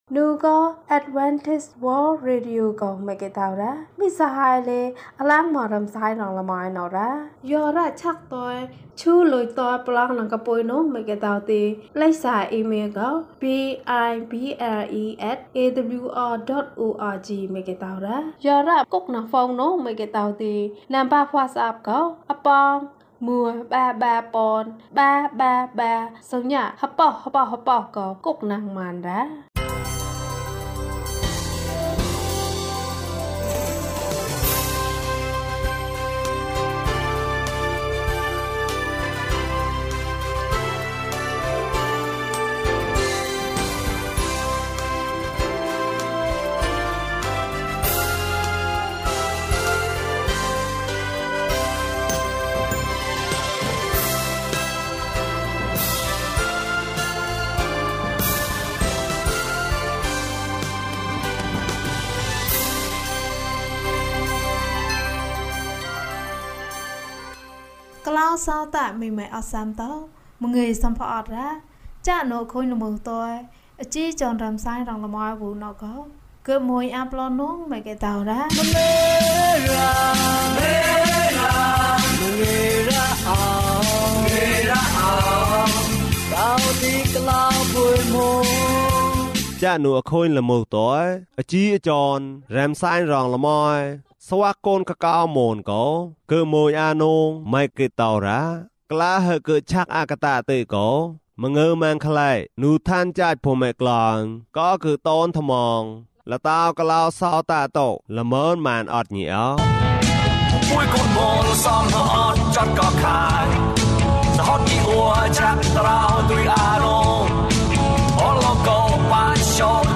မင်းရဲ့ ကြင်နာမှုကို မျှဝေပါ။ အပိုင်း ၁ ကျန်းမာခြင်းအကြောင်းအရာ။ ဓမ္မသီချင်း။ တရားဒေသနာ။